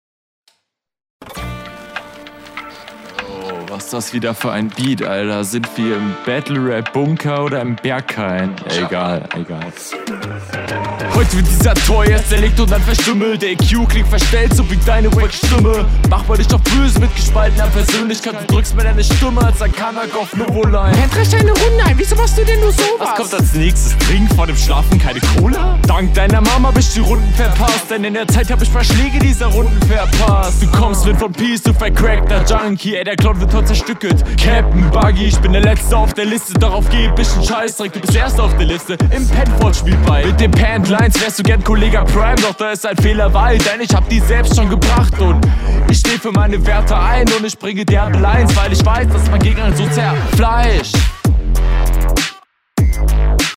Kommst leider im Vergleich wesentlich schlechter auf dem Beat.
Flow ein bisschen druckloser als beim Gegner.